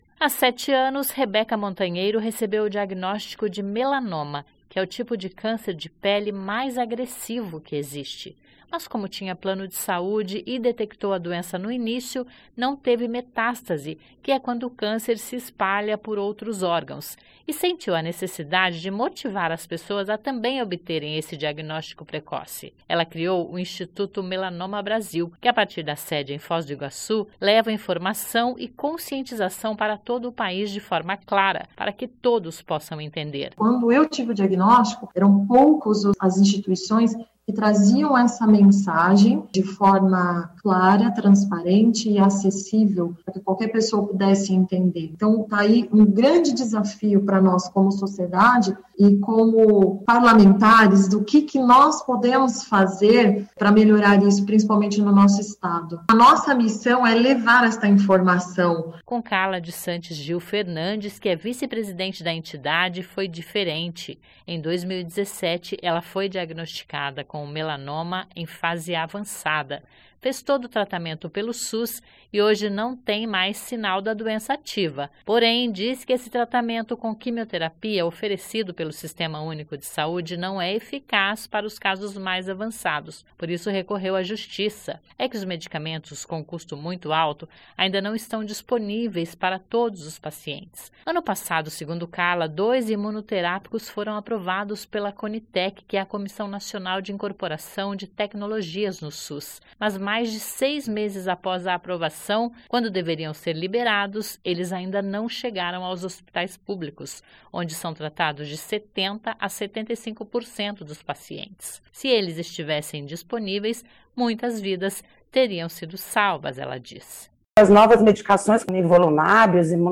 Audiência pública realizada pela Assembleia Legislativa discutiu os perigos do melanoma.
(Sobe som)
O deputado Doutor Batista (DEM), presidente da Comissão de Saúde Pública da Assembleia Legislativa do Paraná, demonstrou preocupação com o fato e colocou a Casa à disposição para apoiar essa liberação junto ao Ministério da Saúde.